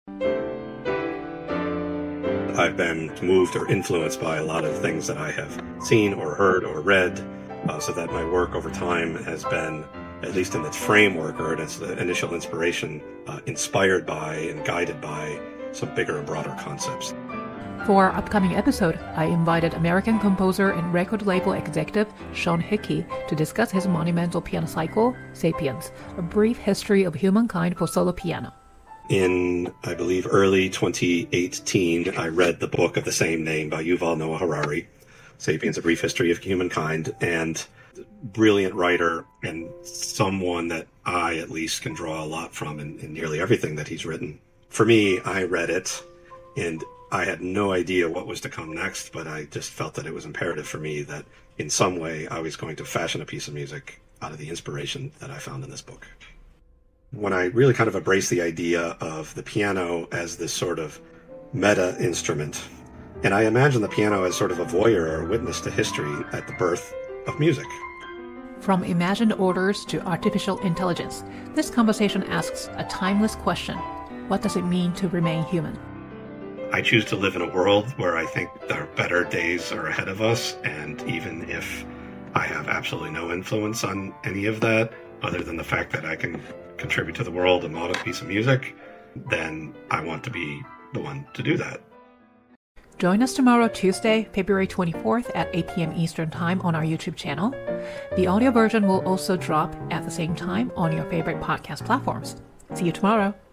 From imagined orders and human cooperation to empire, biology, AI, and the future of artistic sustainability, this conversation explores what it means to create — and remain human — in a rapidly accelerating world.